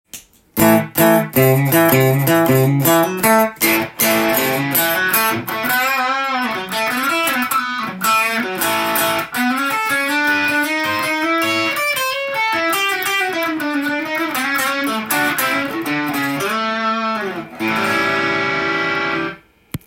音の方は、ブルース系ギタリストが大好きな歪み具合です。
メタルサウンドは厳しいのですが、軽いクランチぐらいの歪みでしたら